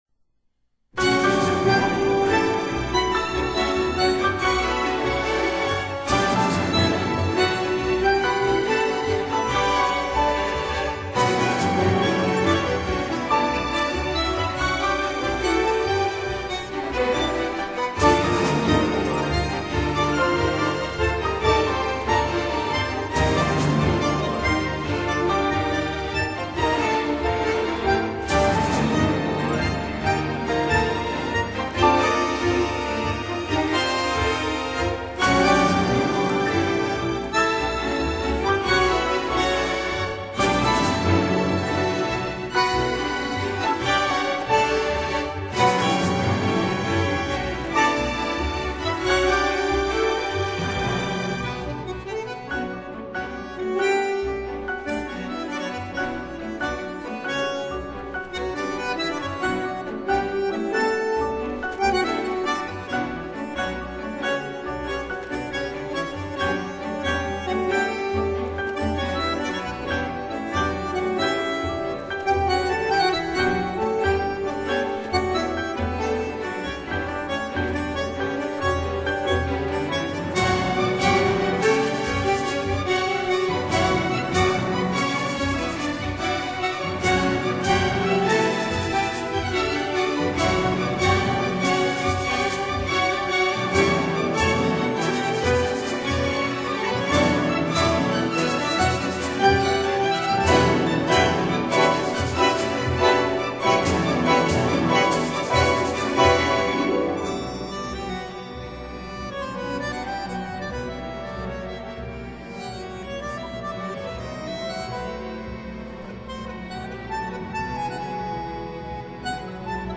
bandoneon